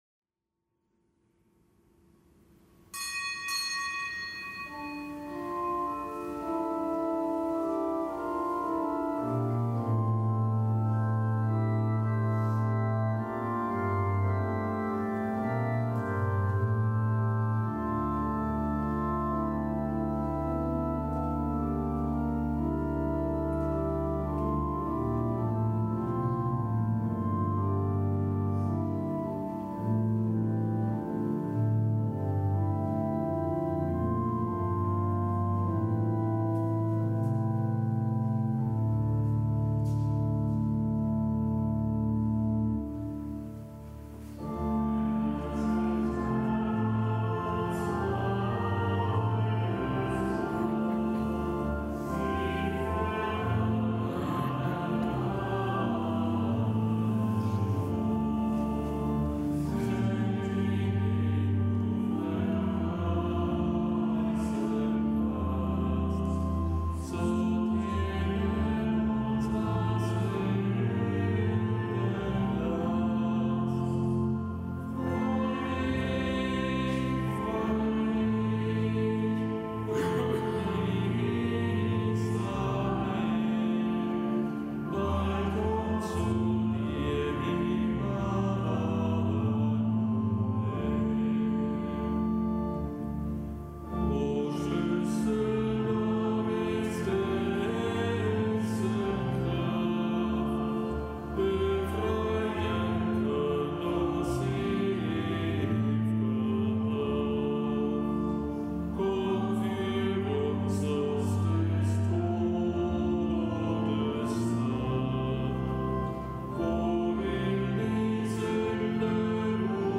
Kapitelsmesse am Montag der vierten Adventswoche
Kapitelsmesse aus dem Kölner Dom am Montag der vierten Adventswoche.